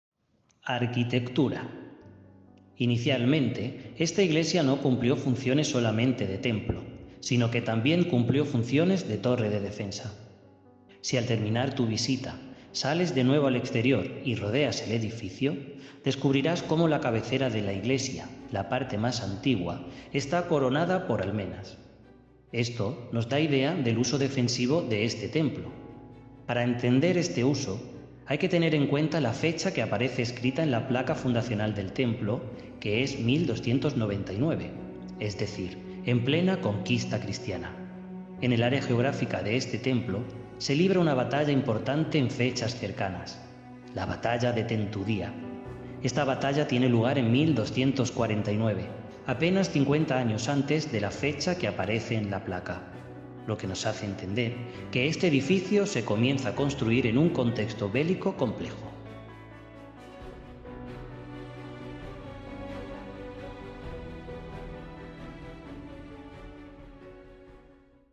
Para mejor uso y disfrute colóquese los auriculares y prueba esta experiencia de sonido envolvente con tecnología 8D. For better use and enjoyment, put on the headphones and try this surround sound experience with 8D technology.